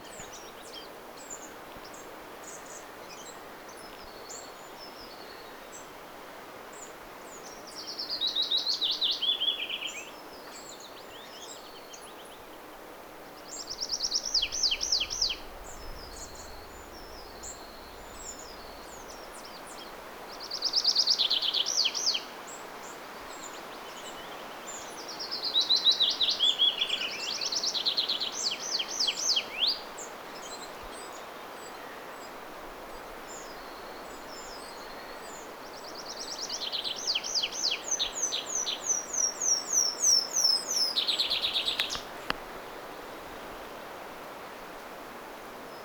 harmaasiepon laulua
taustalla_harmaasiepon_laulua.mp3